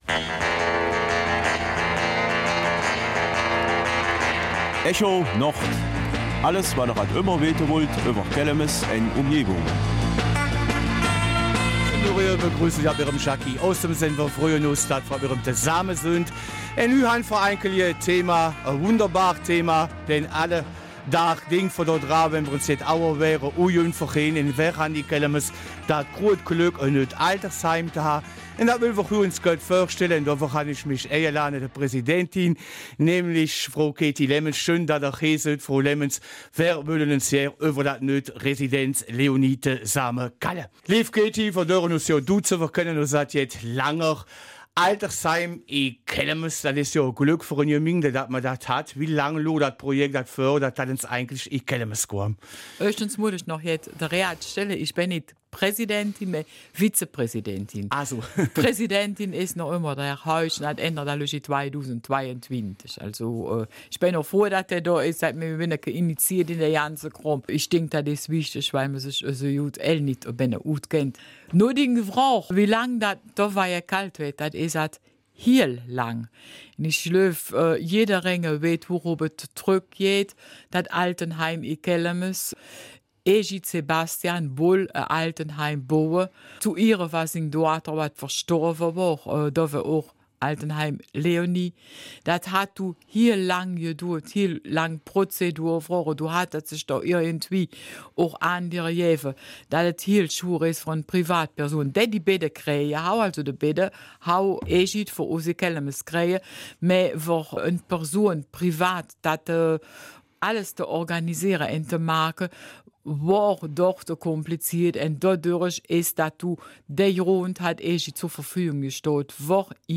Kelmiser Mundart: Haus Leoni